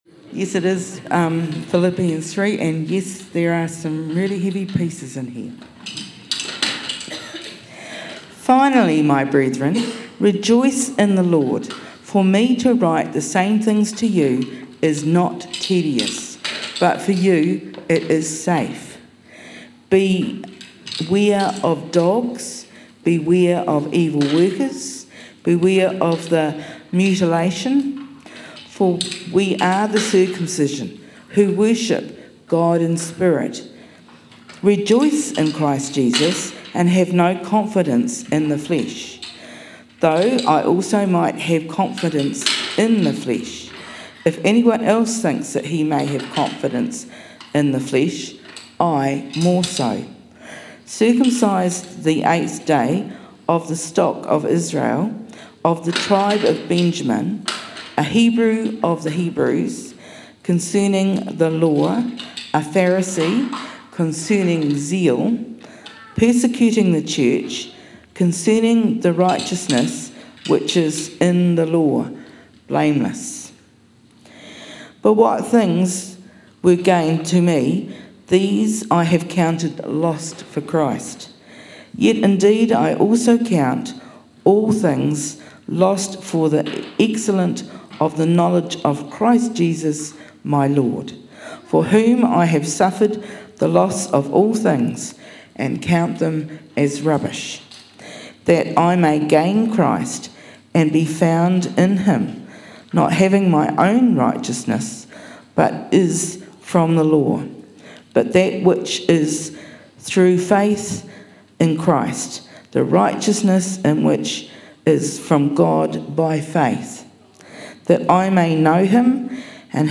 Sermons | Whanganui Anglicans